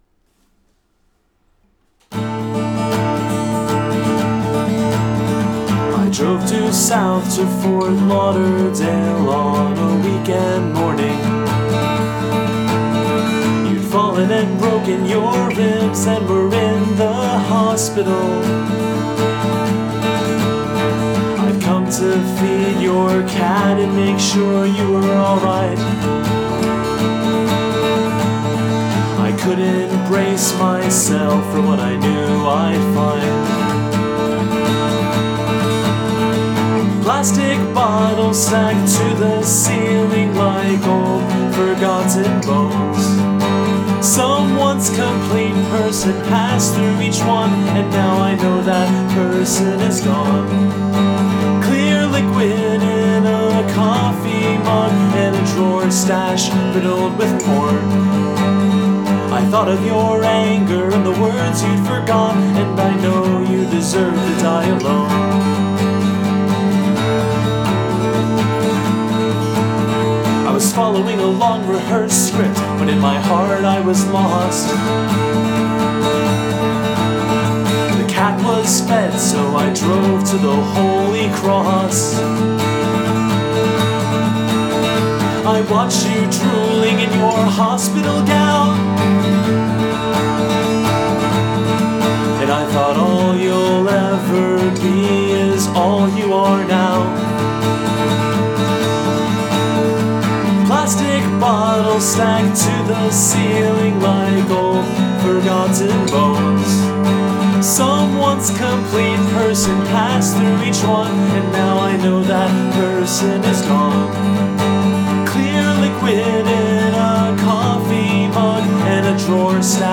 Diss Track
Your actual recording has improved each round. Guitar playing is great, and the vocals are clear as a bell.
The bareness of G&G arrangements means you don't have anywhere to hide shabby songwriting or performances; and this one sounds really good, nicely recorded and performed.
And I like the little exhausted breath you left in there at the end.